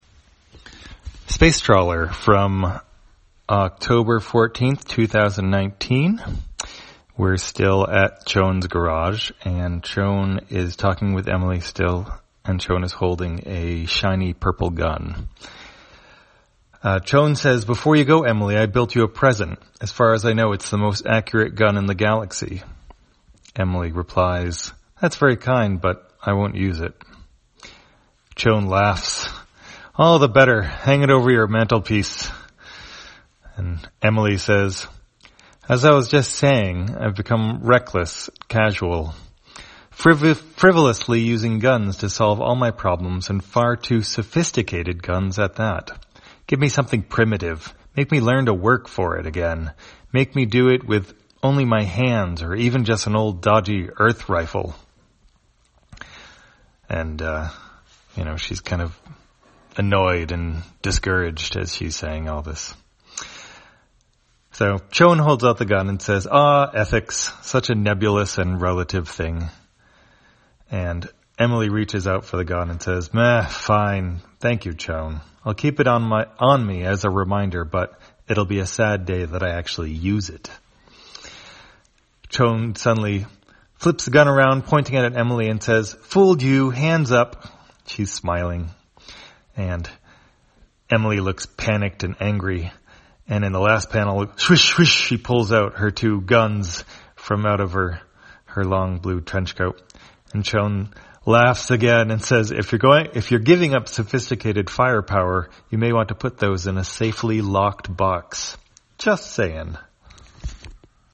Spacetrawler, audio version For the blind or visually impaired, October 14, 2019.